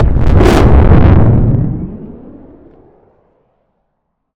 sci-fi_vehicle_thrusters_engage_large_01.wav